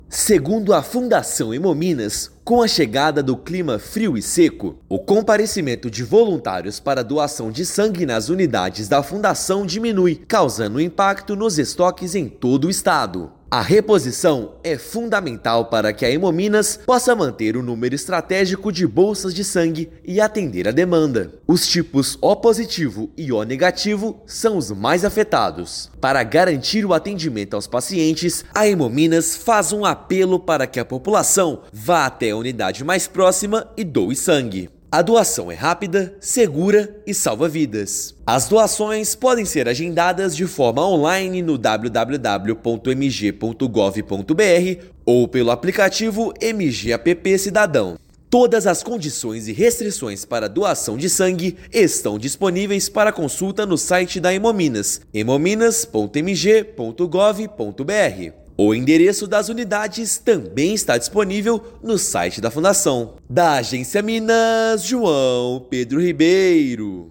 Frio e tempo seco afastam voluntários das unidades; tipos O+ e O- são os mais afetados. Ouça matéria de rádio.